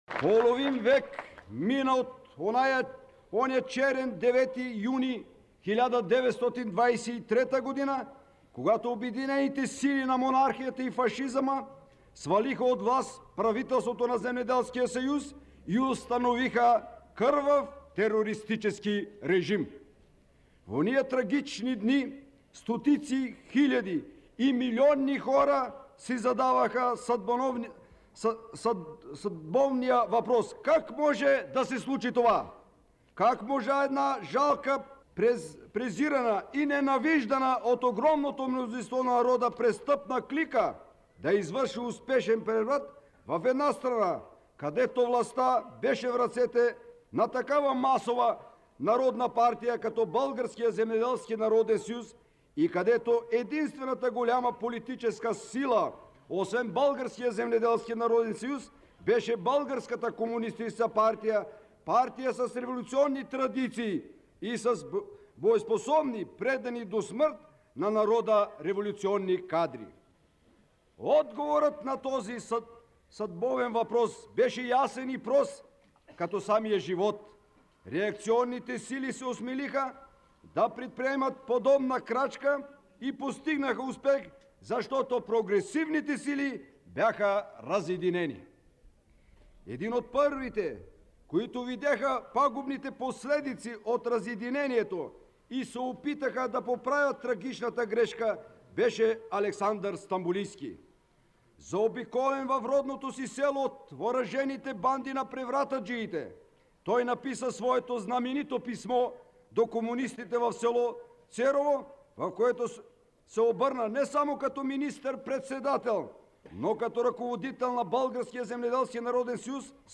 Това са встъпителните думи, преди 50 години на събора в местността „Янини грамади“ край пазарджишкото село Славовица, които първият секретар на ЦК на БКП (Централния комитет на Българската комунистическа партия) Тодор Живков чете слово за деветоюнския преврат и гибелта на Стамболийски. Записът от 10 юни 1973 година е съхранен в архива на Златния фонд на Българското национално радио (БНР).